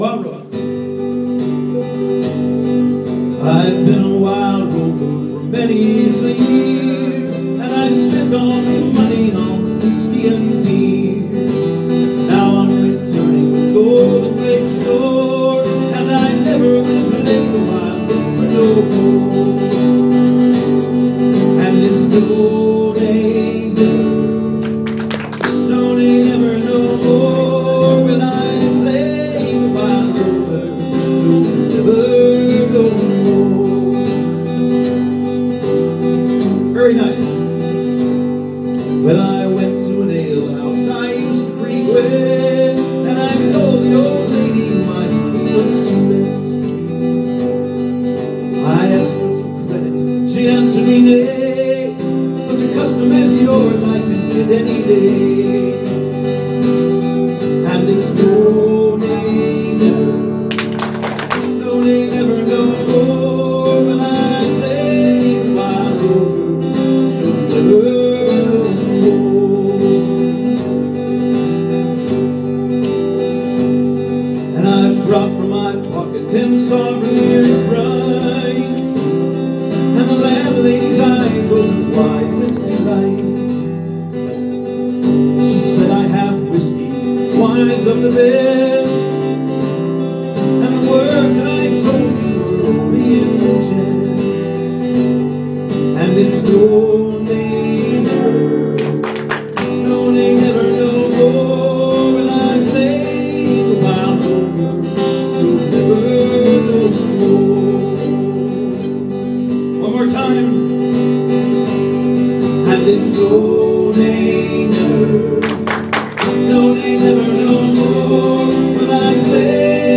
performs "Wild Rover" at the Monroe County History Center as part of the Monroe County Civil War Roundtable.